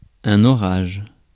Ääntäminen
France: IPA: /ɔ.ʁaʒ/